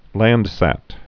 (lăndsăt)